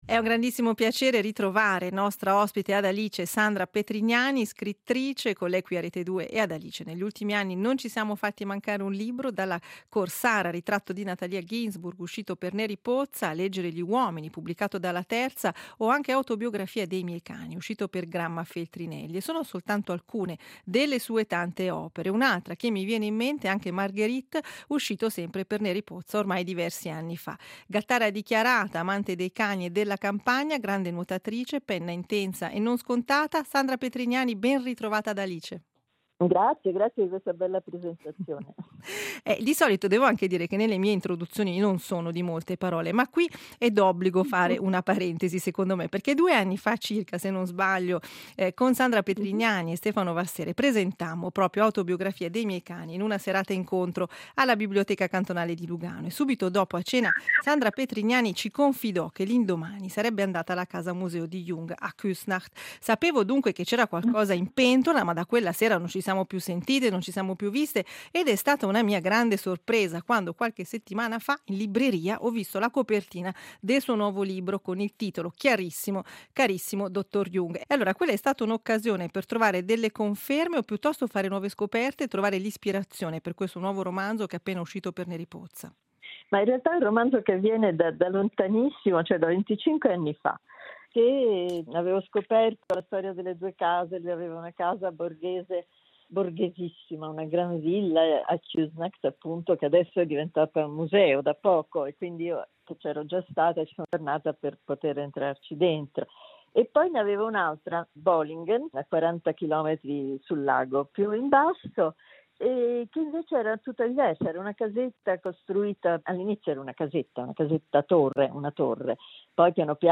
Intervista a Sandra Petrignani